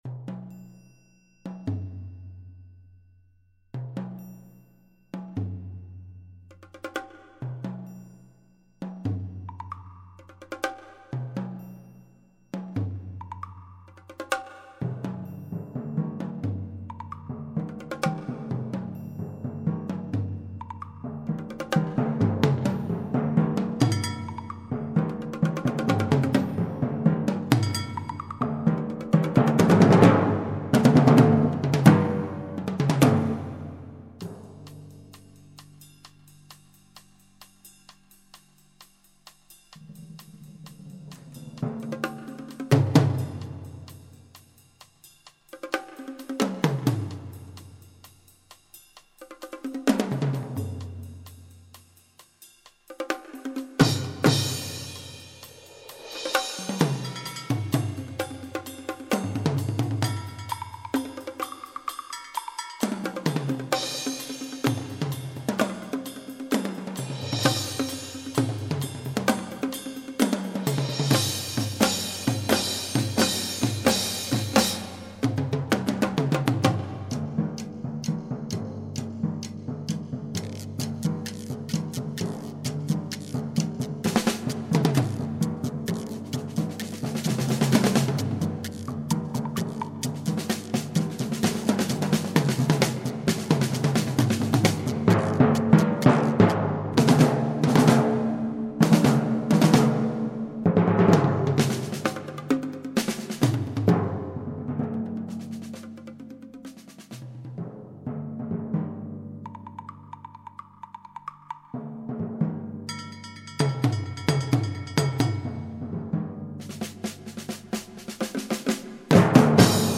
Voicing: Percussion Quartet